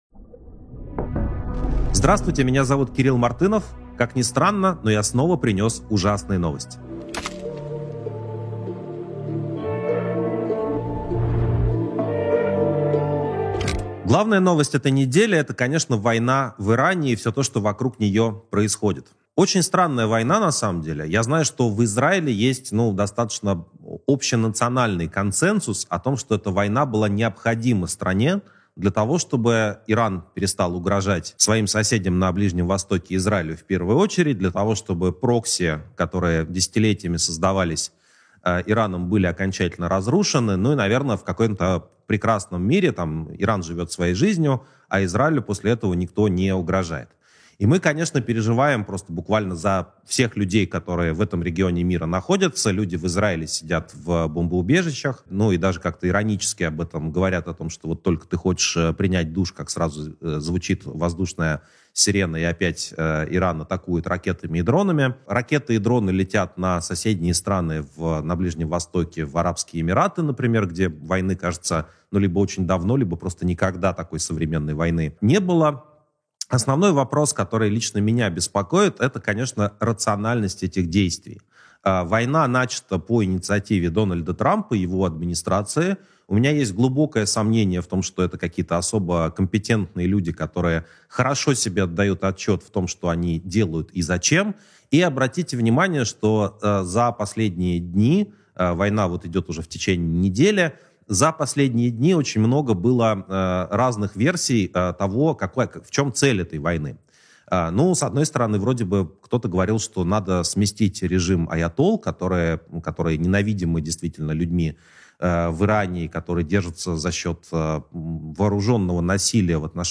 Эфир ведёт Кирилл Мартынов